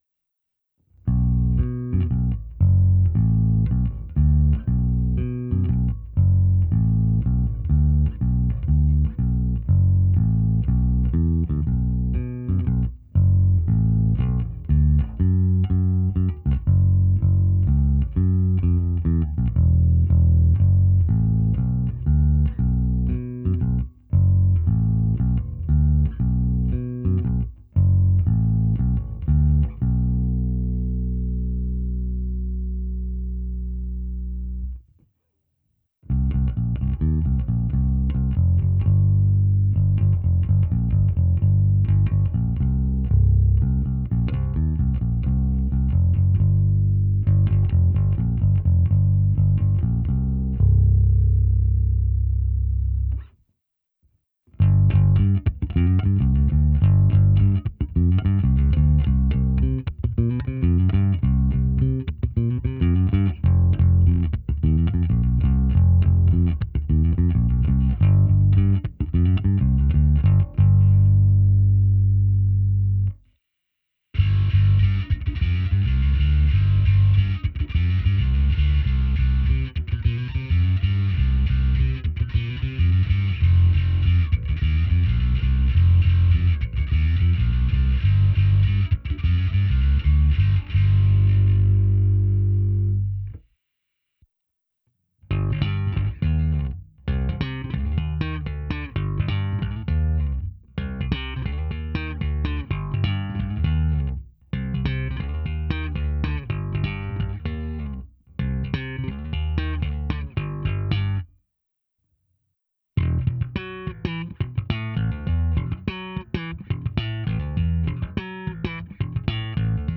Abych simuloval, jak hraje baskytara přes aparát, protáhnul jsem ji preampem Darkglass Harmonic Booster, kompresorem TC Electronic SpectraComp a preampem se simulací aparátu a se zkreslením Darkglass Microtubes X Ultra. V nahrávce jsem použil zkreslení a také hru slapem a ukázku na struně H.
Ukázka se simulací aparátu